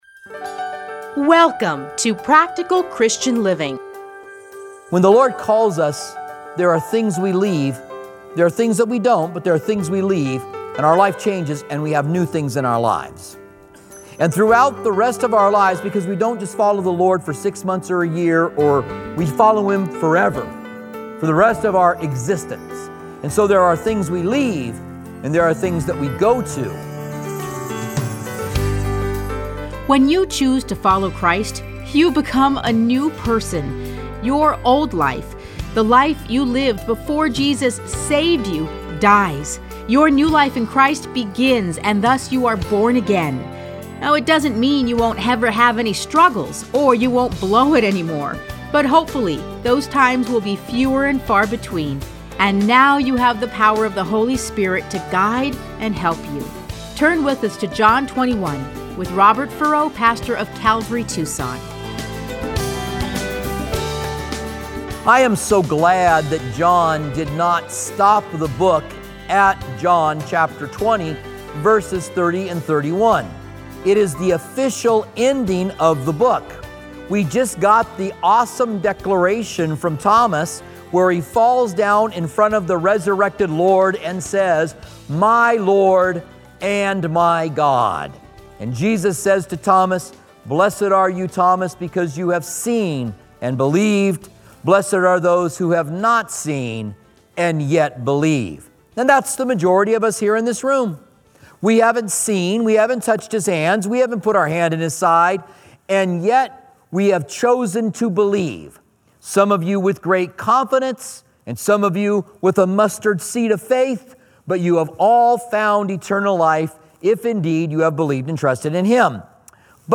Listen to a teaching from John 21:1-14.